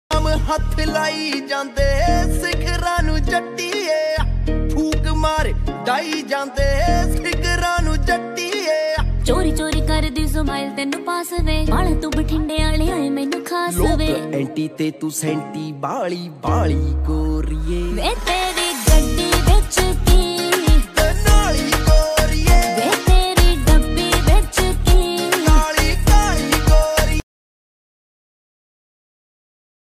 Punjabi ringtone